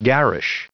Prononciation du mot garish en anglais (fichier audio)
Prononciation du mot : garish